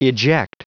Prononciation du mot eject en anglais (fichier audio)
Prononciation du mot : eject